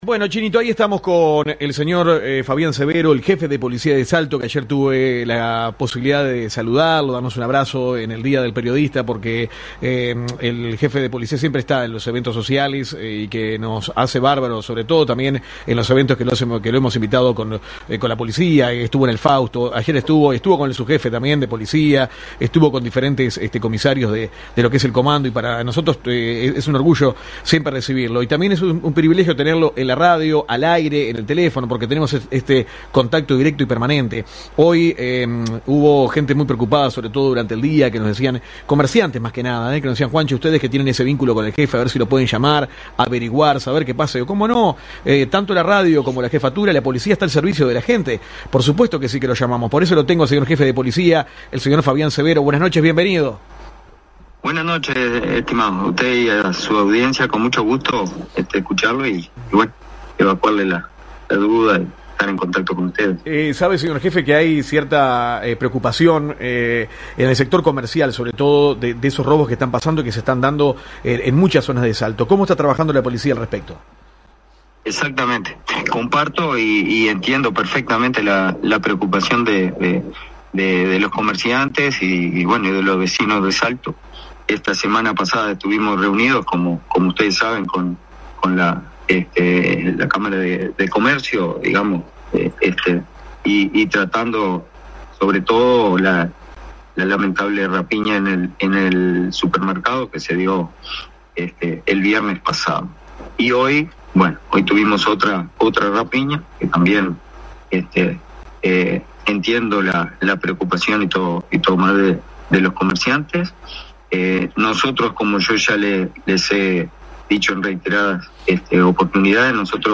Dialogamos telefónicamente con el Jefe de Policía de Salto Comisario General (R) Fabián Severo – TEMPLARIA PLUS ON LINE RADIO